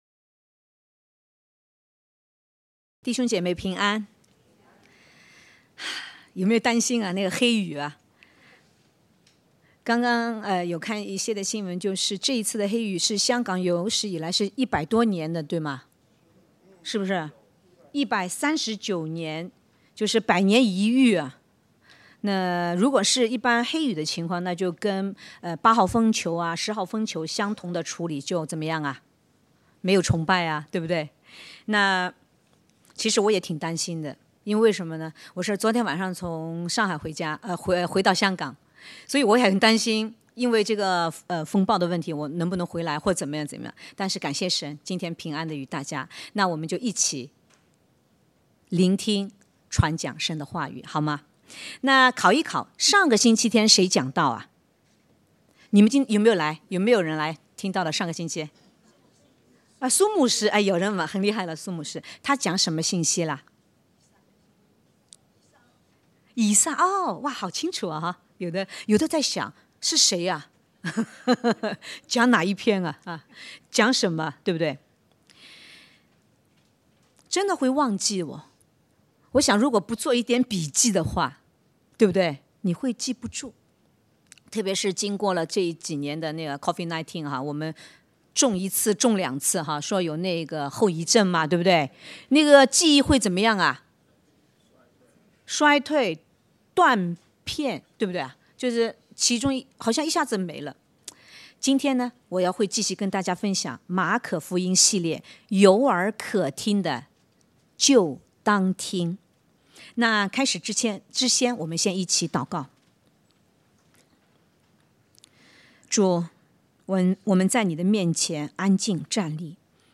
華語崇拜（荔枝角) - 有耳可聽就當聽！
證道重溫